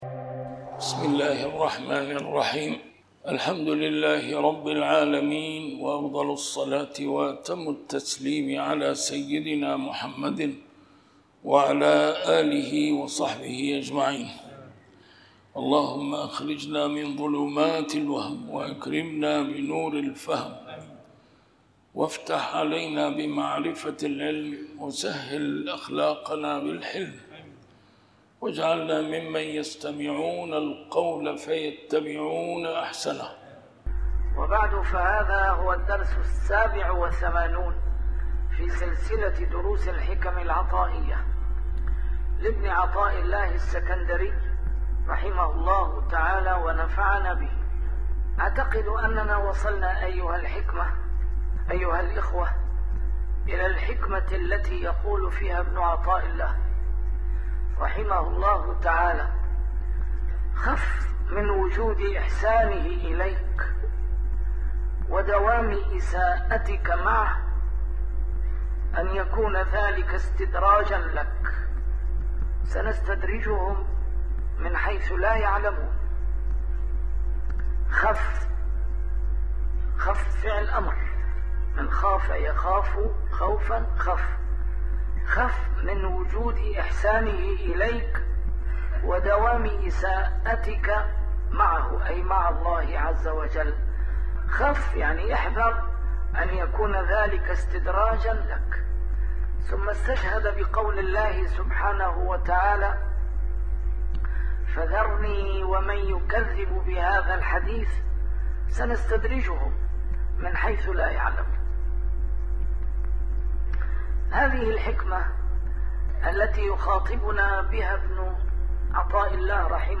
A MARTYR SCHOLAR: IMAM MUHAMMAD SAEED RAMADAN AL-BOUTI - الدروس العلمية - شرح الحكم العطائية - الدرس رقم 87 شرح الحكمة 65